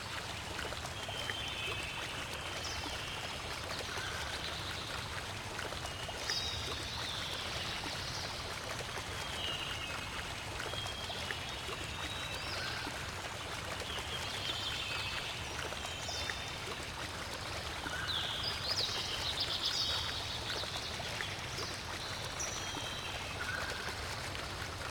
lake.ogg